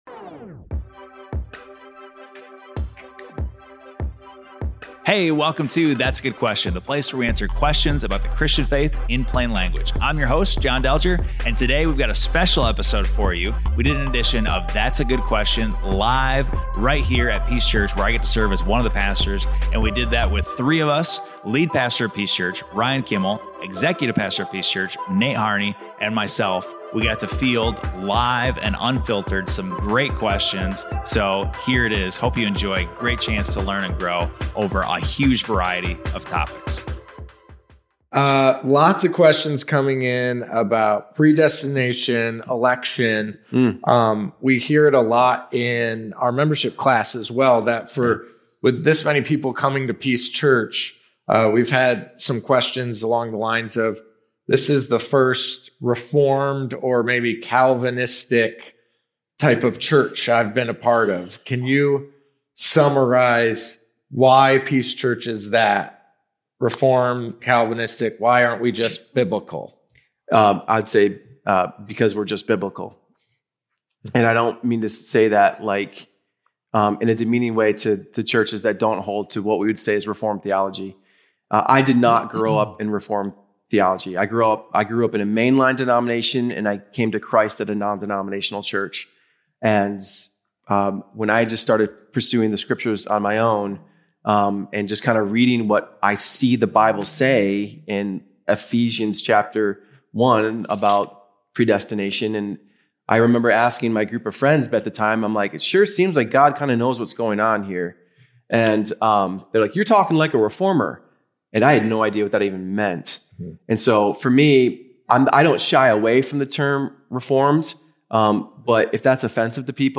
This conversation challenges common assumptions, explores what it means to live with gospel-centered priorities, and encourages believers to pursue a deeper, more faithful life in Christ.